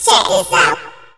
CHECKVOX  -R.wav